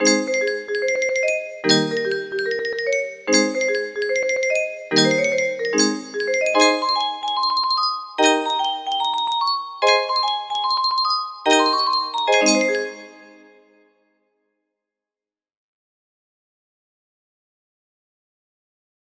Skip To My Lou music box melody
Full range 60